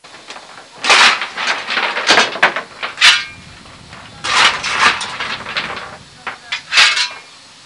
Office Ambiance